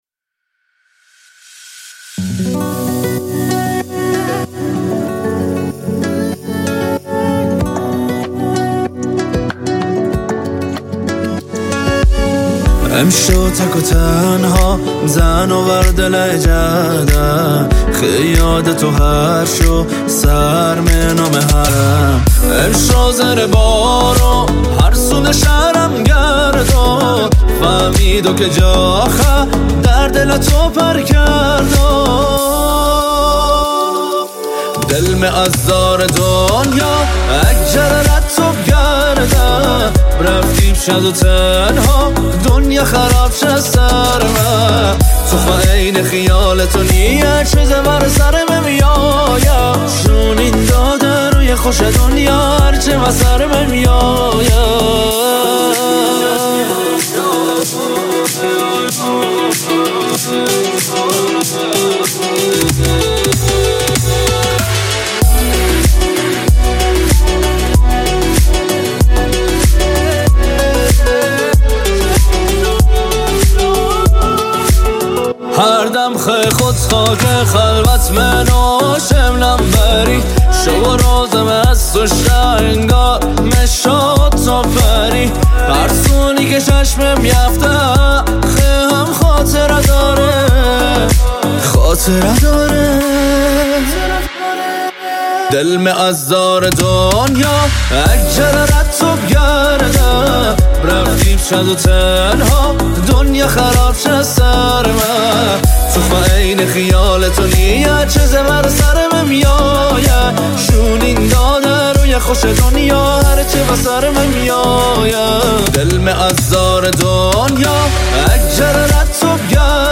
آهنگ محلی